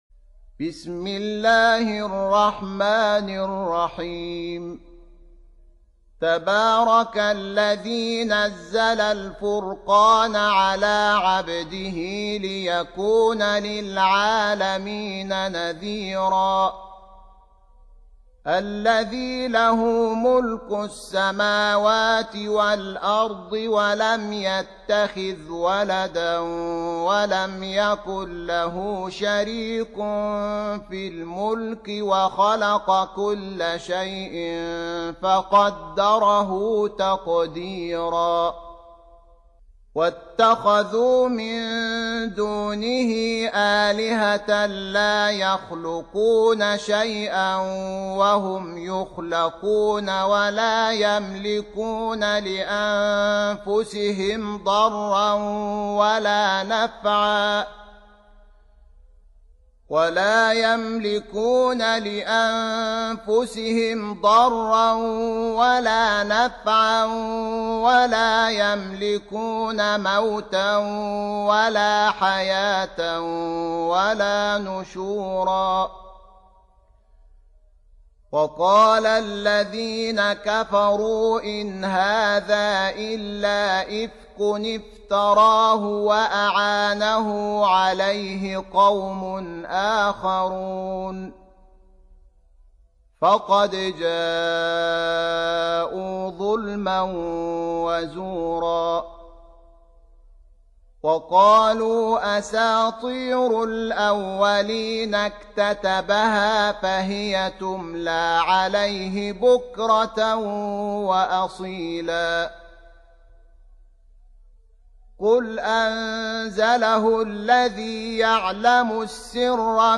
Surah Repeating تكرار السورة Download Surah حمّل السورة Reciting Murattalah Audio for 25.